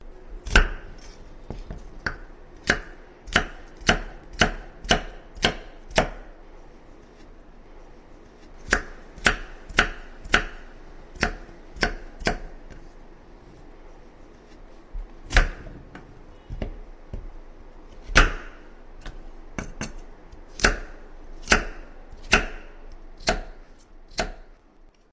Tiếng Cắt, Thái Cà rốt, Rau củ quả… trên thớt
Thể loại: Tiếng ăn uống
Description: Tiếng dao rạch thớt, tiếng củ quả bị bổ cắt, thái mỏng hay xắt khúc không chỉ làm tăng tính chân thực mà còn kích thích thị giác và thính giác người xem.
tieng-cat-thai-ca-rot-rau-cu-qua-tren-thot-www_tiengdong_com.mp3